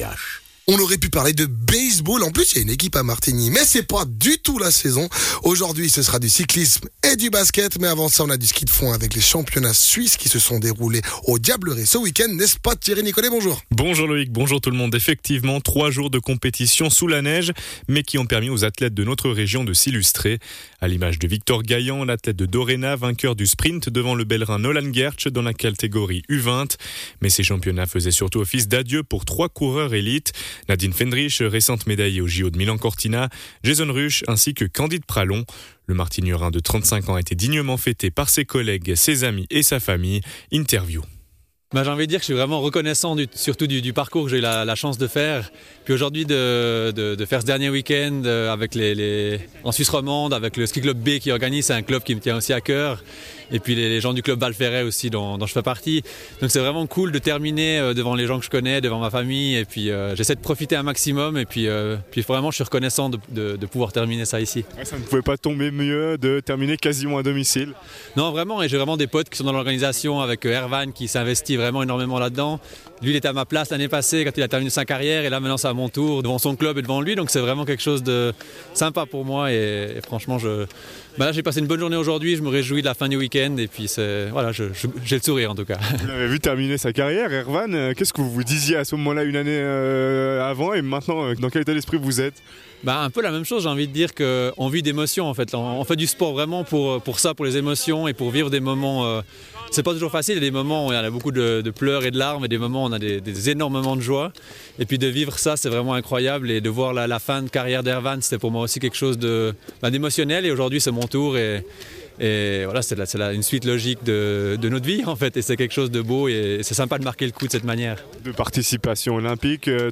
fondeur professionnel